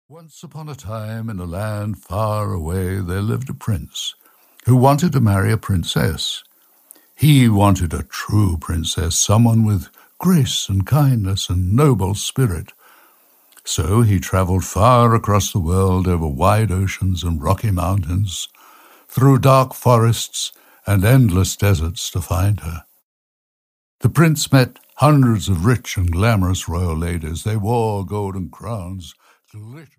Audio knihaThe Princess and the Pea (EN)
Ukázka z knihy
• InterpretSir Roger Moore